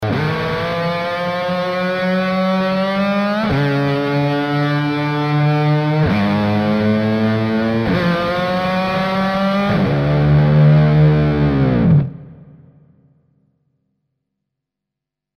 Formula 1   mp3 15'' 241 Kb